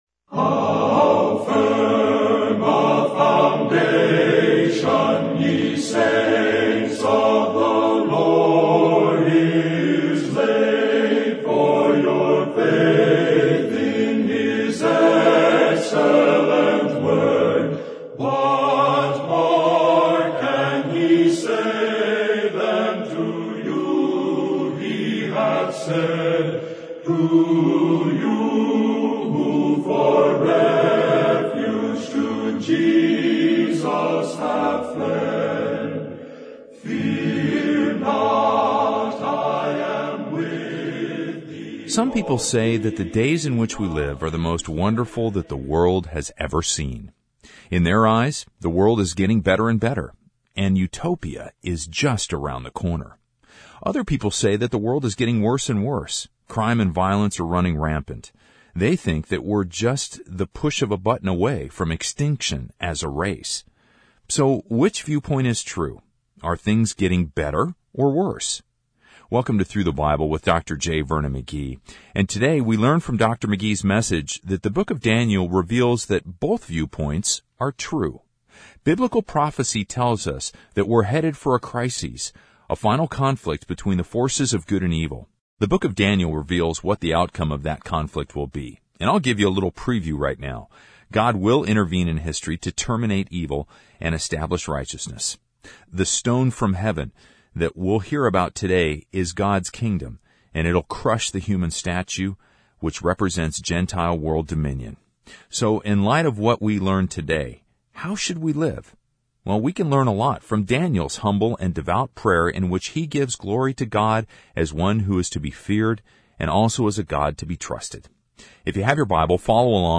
TTBR_Sermon_2188_Gods_Calendar_for_Israel.mp3